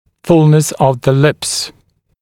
[‘fulnəs əv ðə lɪps][‘фулнэс ов зэ липс ]полнота губ